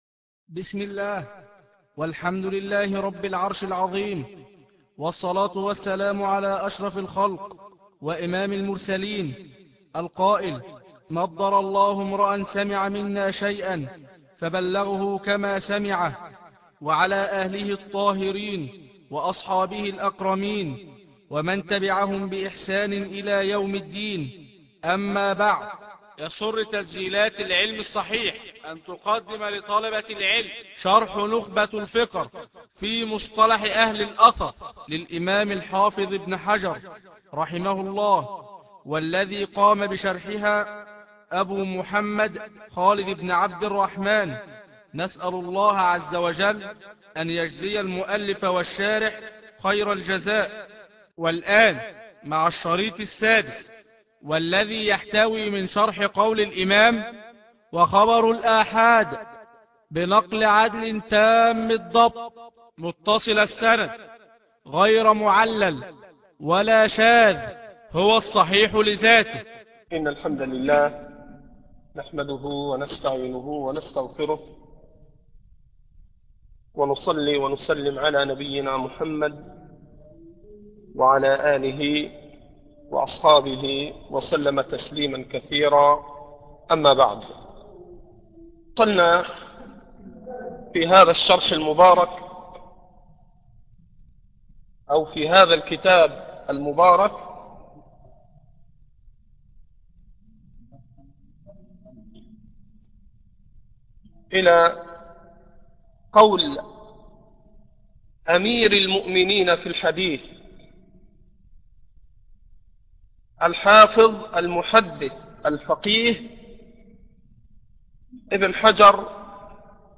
شرح نخبة الفكر الدرس 11